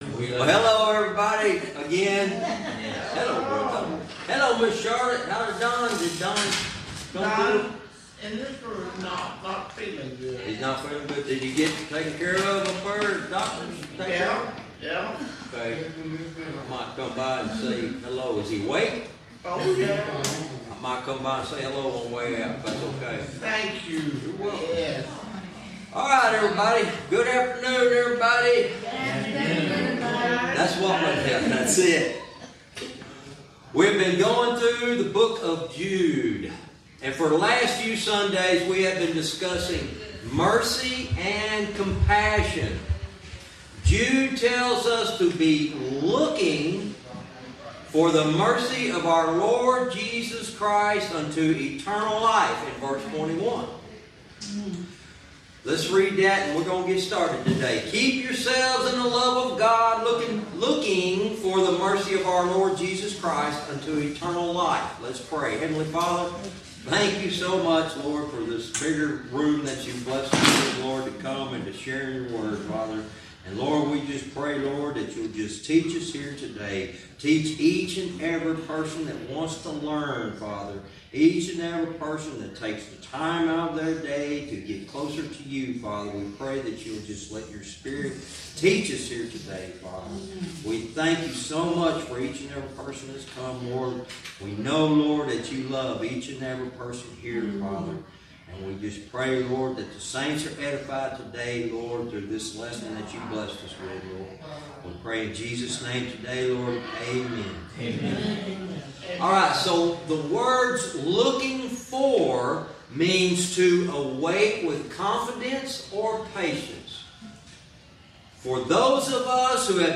Verse by verse teaching - Jude lesson 102 verse 23